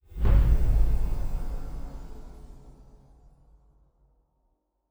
Special Click 01.wav